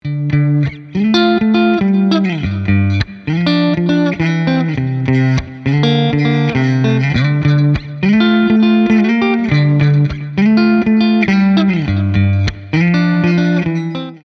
Guitar Tones
Rhythm Tone